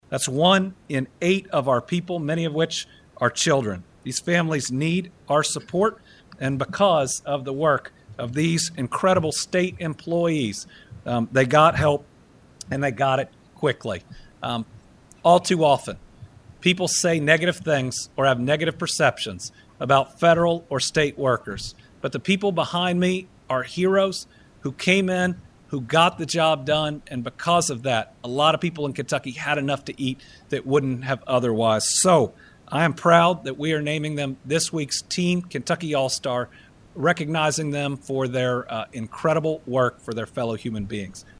Governor Andy Beshear addressed the impact of the recent federal government shutdown and updates on SNAP benefits during his Team Kentucky Update on Thursday.